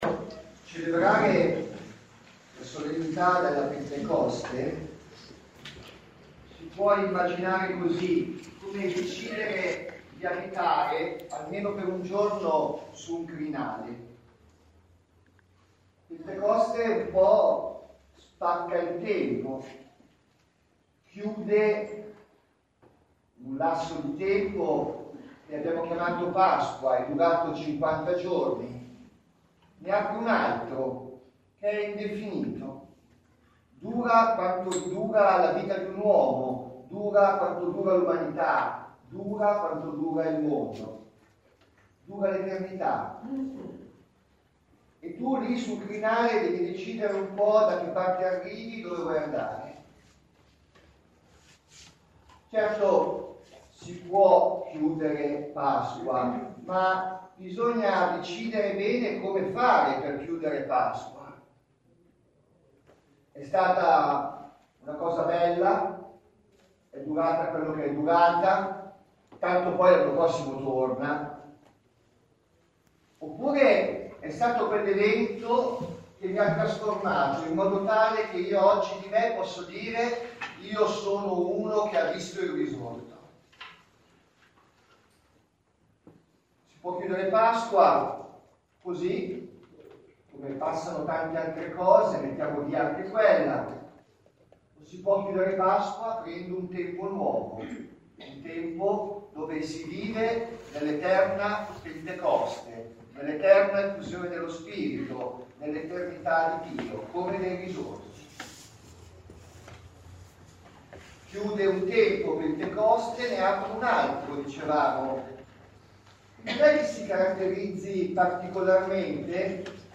Omelia Pentecoste 2018
Omelia della Celebrazione Eucaristica in occasione delle Pentecoste a Premaggi, in festa per Maria (Ascolta file .mp3)